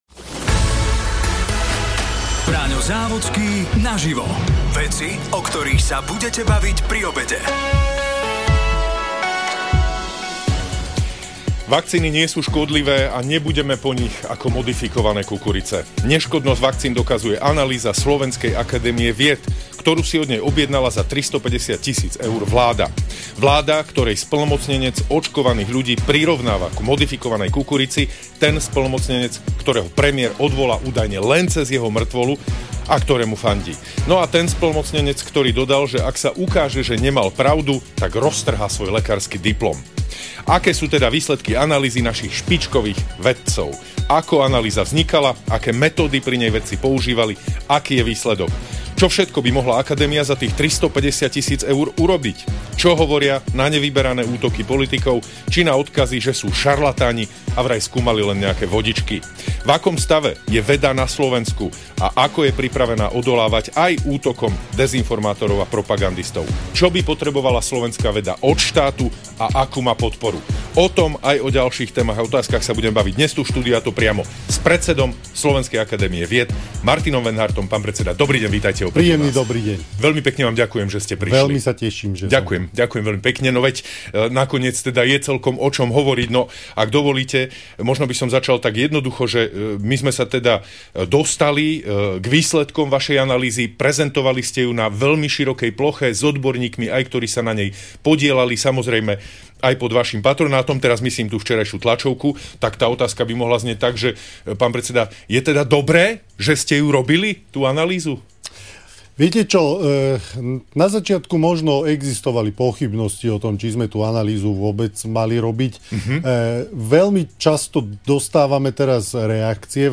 Braňo Závodský sa rozprával s predsedom Slovenskej akadémie vied Martinom Venhartom.